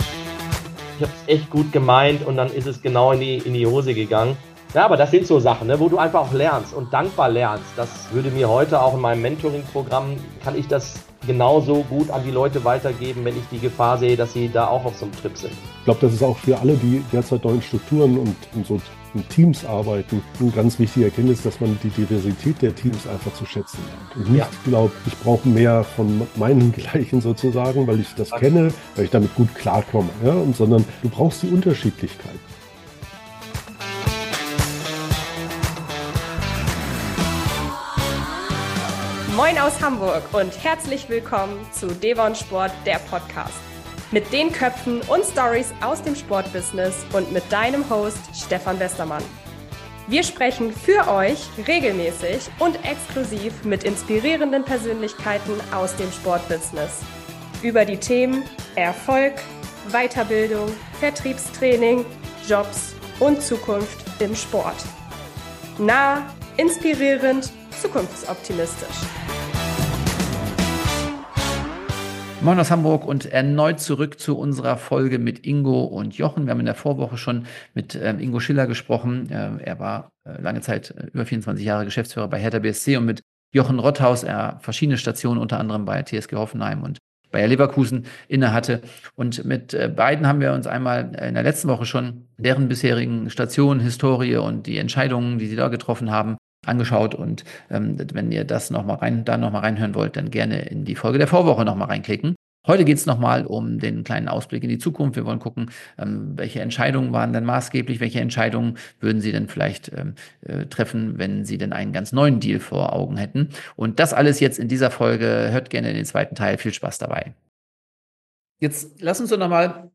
Beschreibung vor 1 Jahr In der letzten Episode sind wir in Teil des Interviews gestartet: Karriere im Sportbusiness früher und heute?